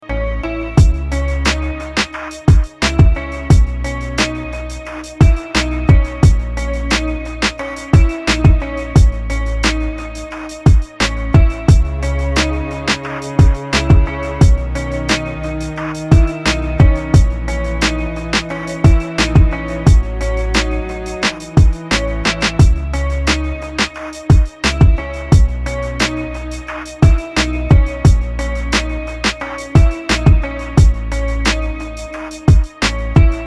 • Качество: 192, Stereo
без слов
тревожные
виолончель
Bass
instrumental hip-hop
лиричные
Самодельная инструменталка